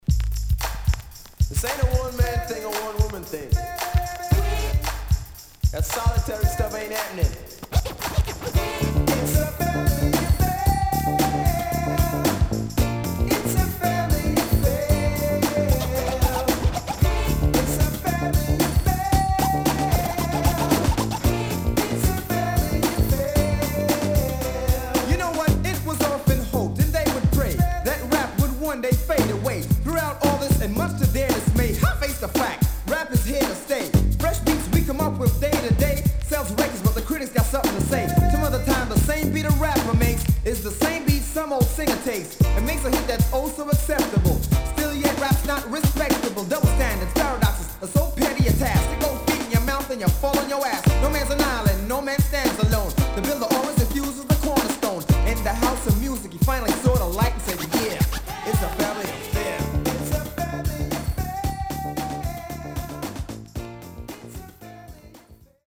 HOME > DANCEHALL
Nice Vocal
Side B:所々チリノイズ入ります。